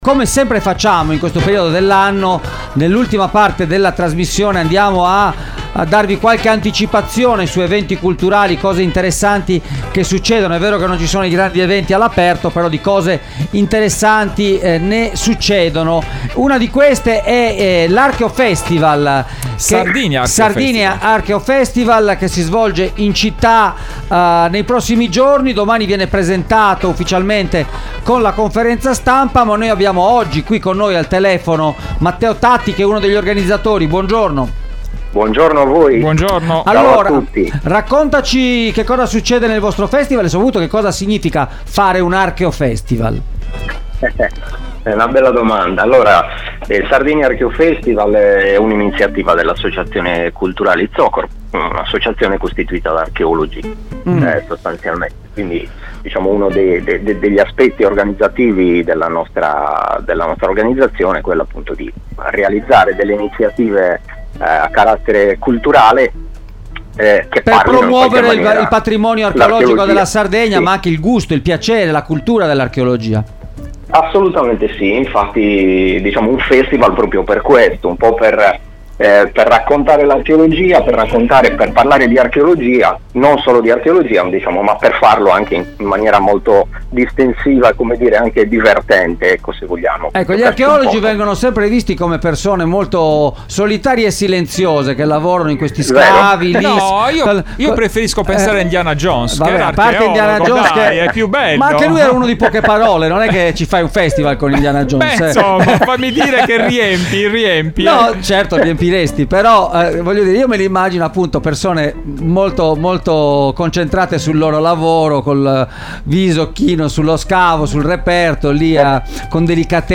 I racconti e i saperi del mare nell’edizione 2022 del Sardinia Archeo Festival – Intervista